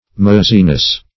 Maziness \Ma"zi*ness\, n. The state or quality of being mazy.